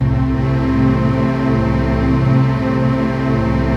CHRDPAD004-LR.wav